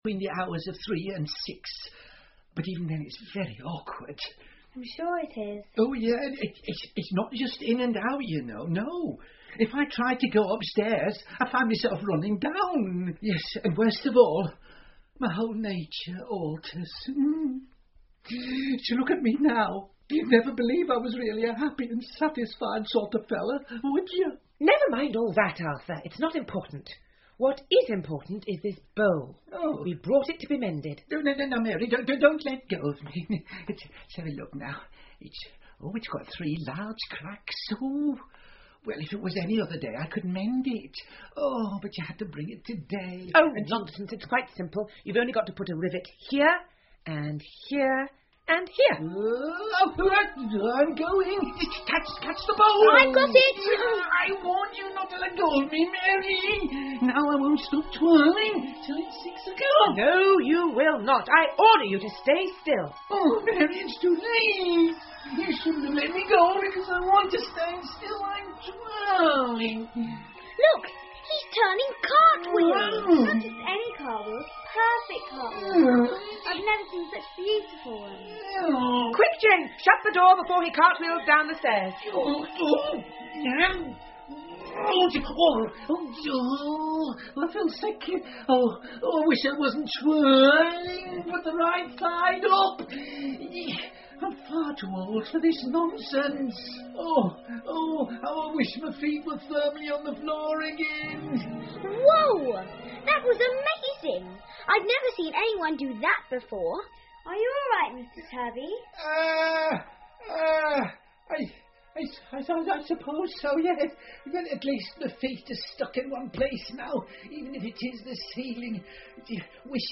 玛丽阿姨归来了 Mary Poppins 儿童英文广播剧 11 听力文件下载—在线英语听力室